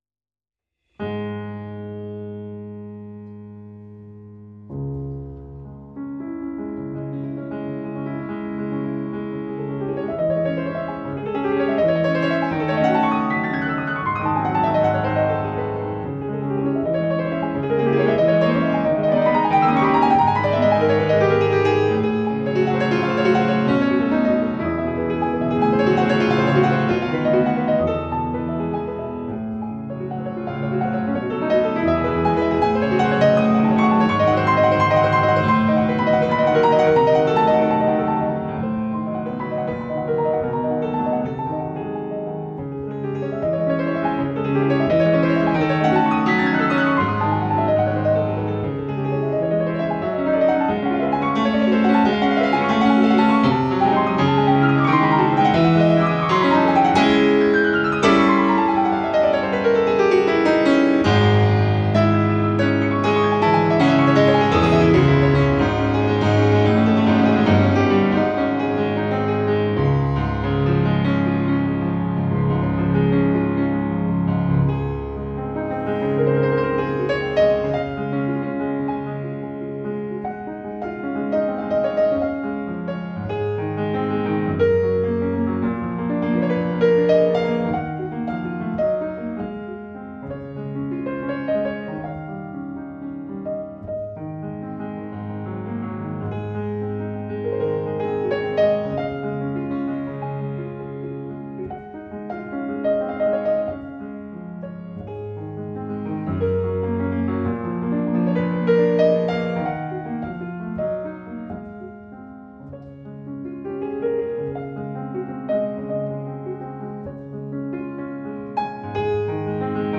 piano
Notes Recorded June 2016 in St. Paul's Hall, Huddersfield University